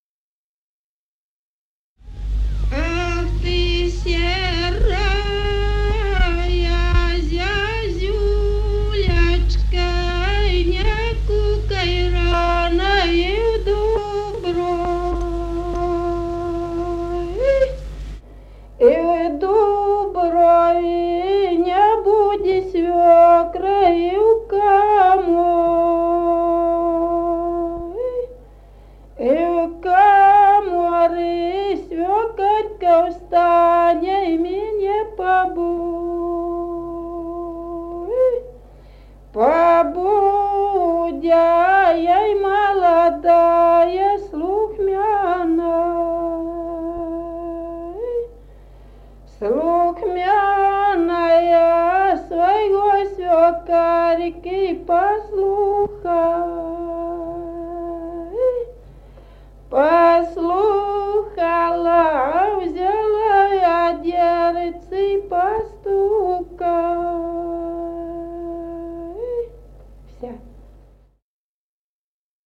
Народные песни Стародубского района «Ах, ты серая зязюлечка», жнивные.